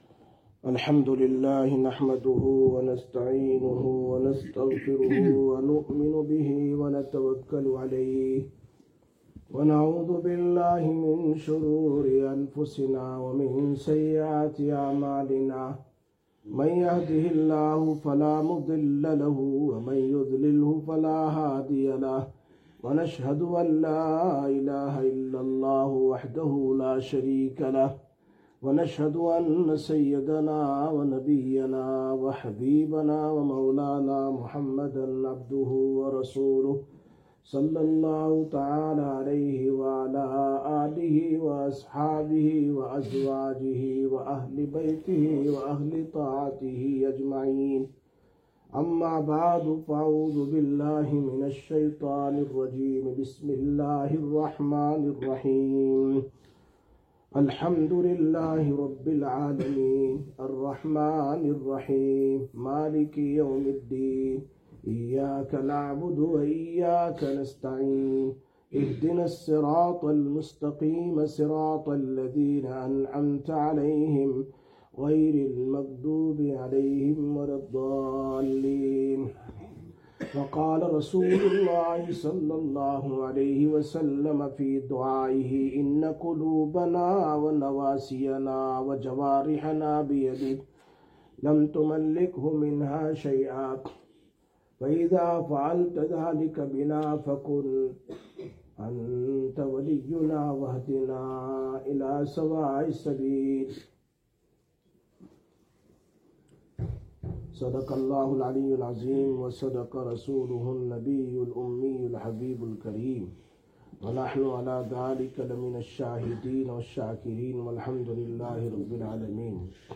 25/04/2025 Jumma Bayan, Masjid Quba